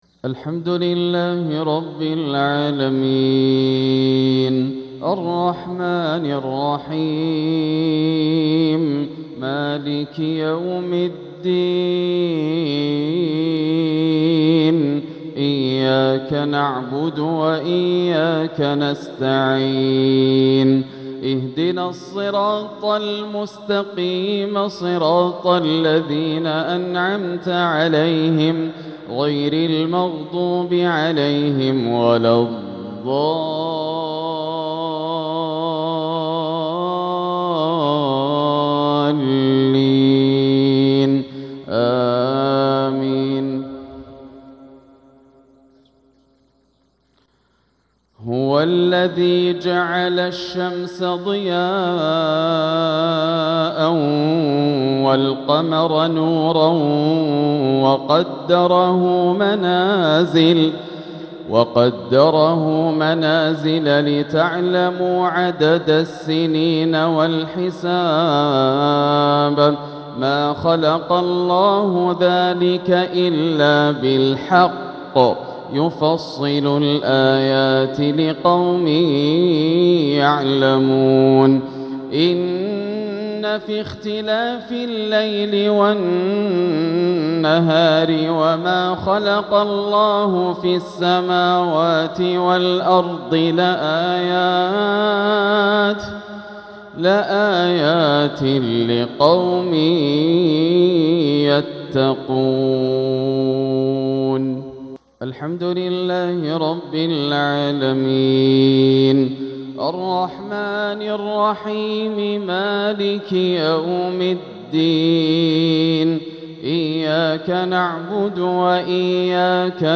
تلاوة ماتعة من سورة يونس | مغرب السبت 8-2-1447هـ > عام 1447 > الفروض - تلاوات ياسر الدوسري